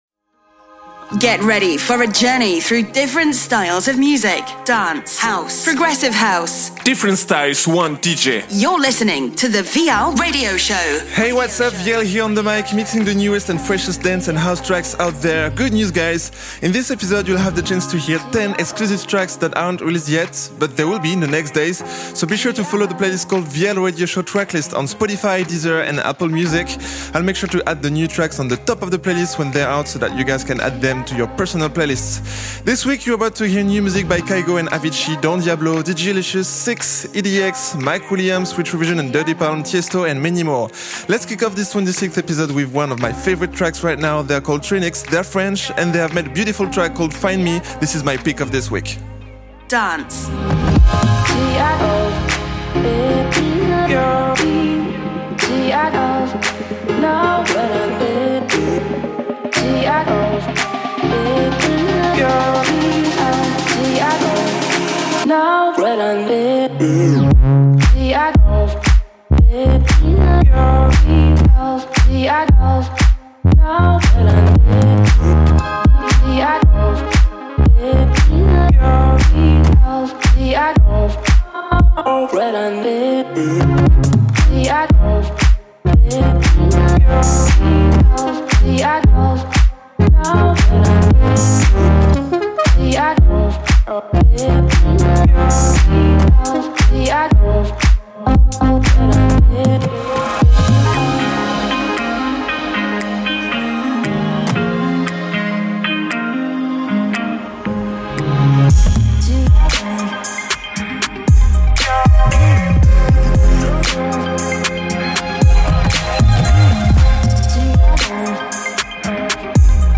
Dance, future house & progressive house DJ mix.